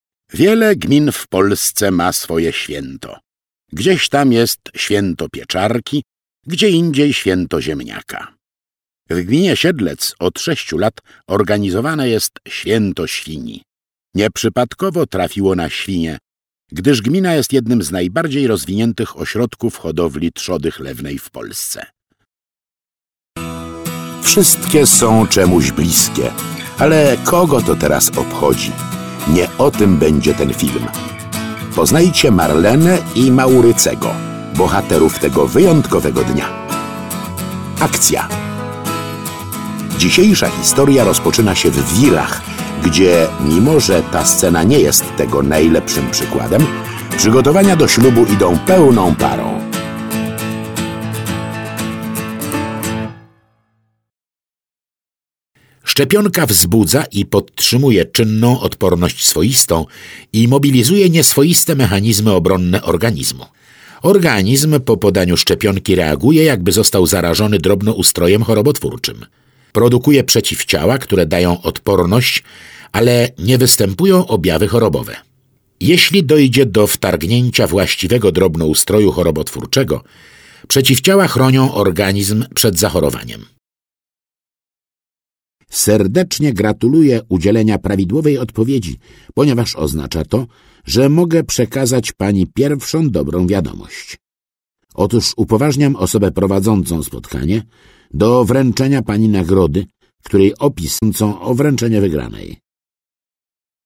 Männlich